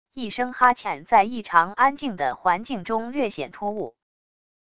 一声哈欠在异常安静的环境中略显突兀wav音频